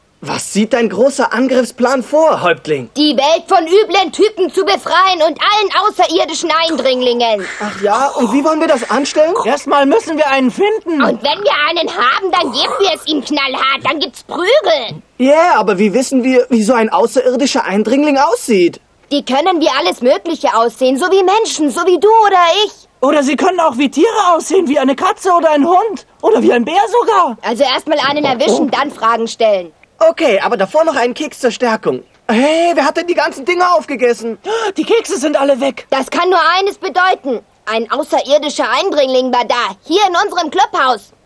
Na da hab ich mir wieder was aufgehalst Über ein Dutzend "Kinderrollen" mit Sprechern, die (wie im Original) wohl keine Kinder mehr sind.